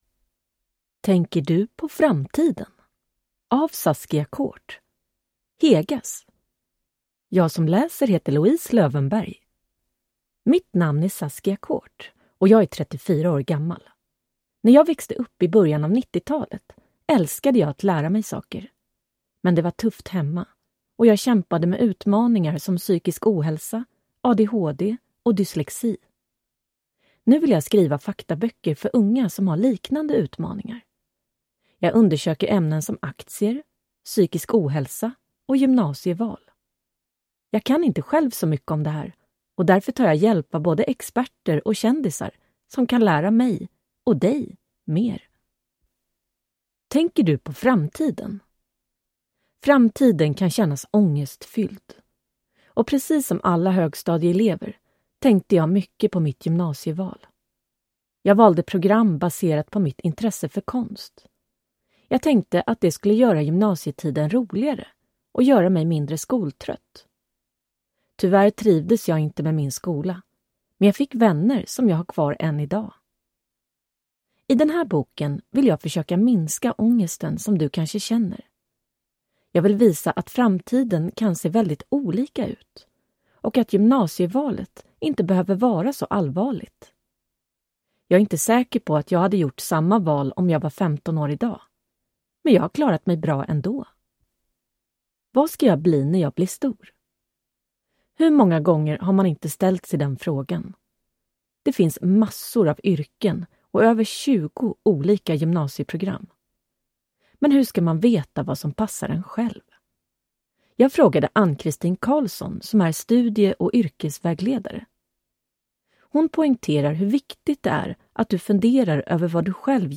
Framtiden – Ljudbok